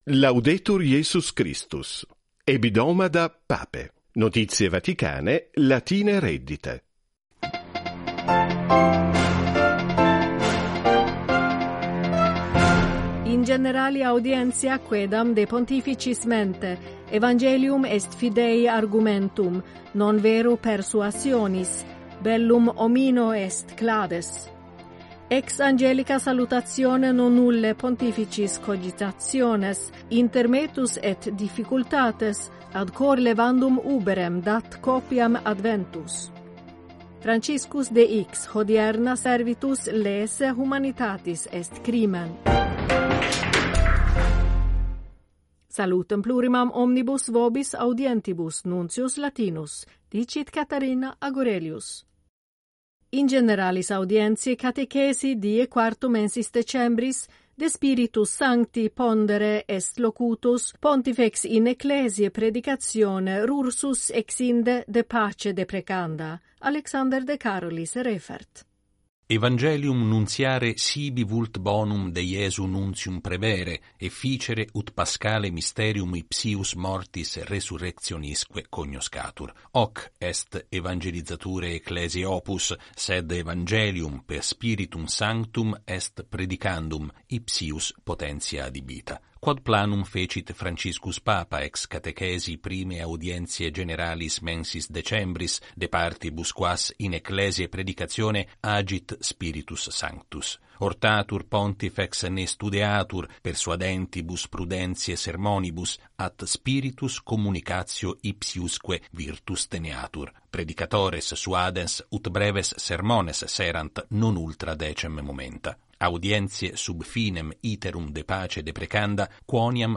Vatican Radio News in Latin